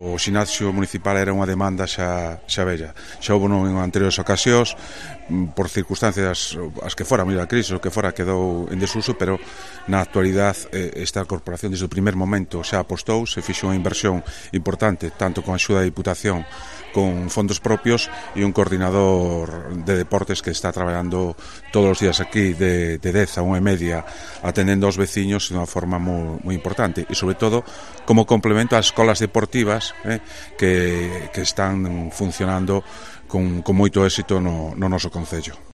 Ángel Alvariño, alcalde de Neda.